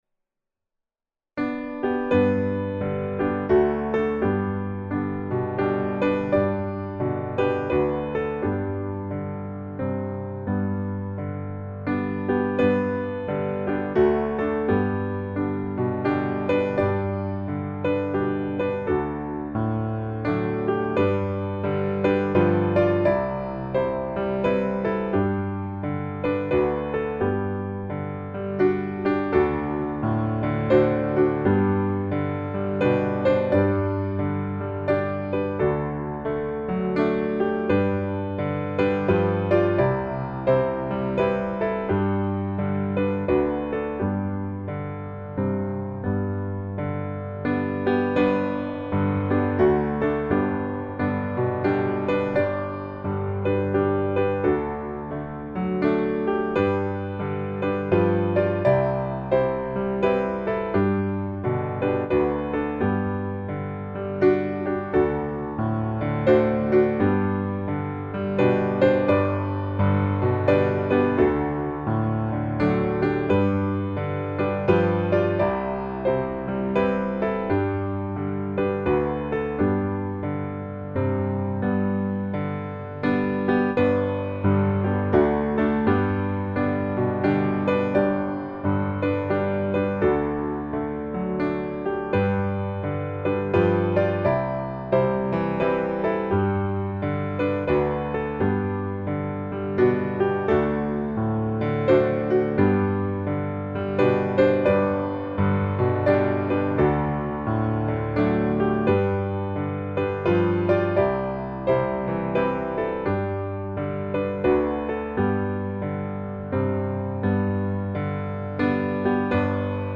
F Major
8.7.8.7. with chorus.